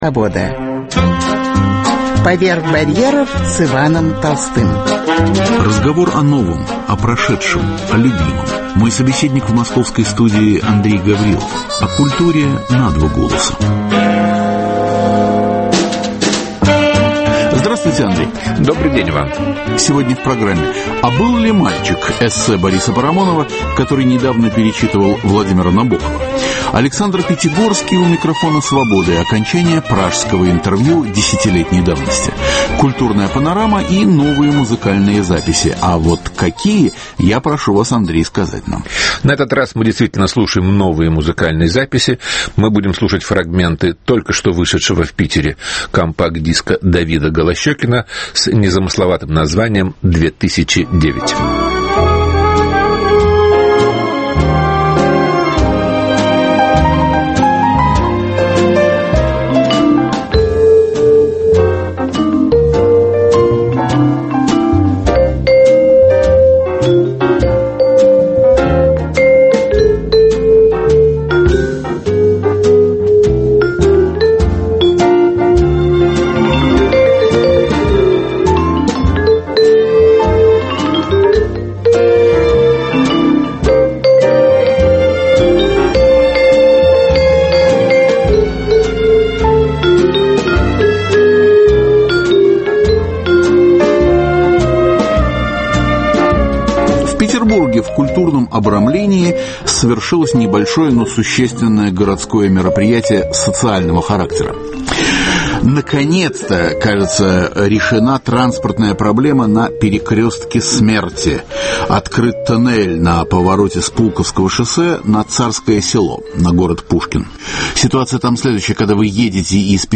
А был ли мальчик? – эссе Бориса Парамонова. Александр Пятигорский у микрофона Свободы – окончание пражского интервью.